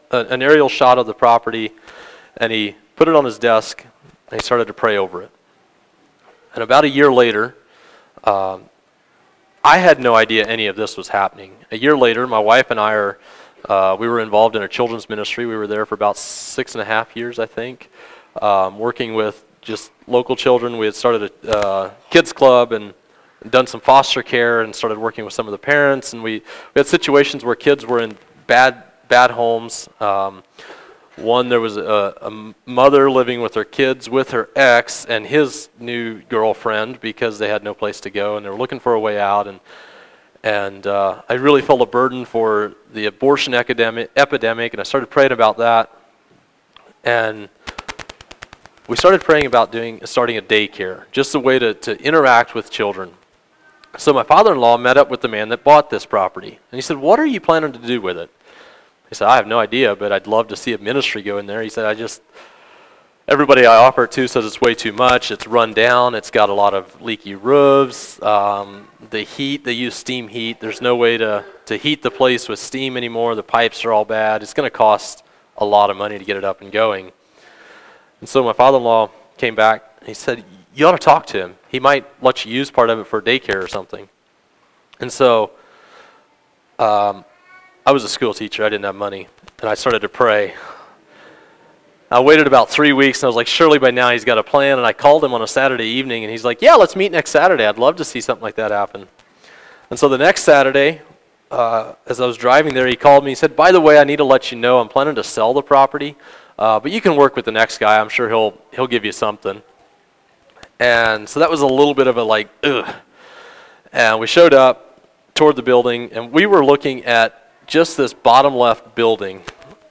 Mission Trip Presentation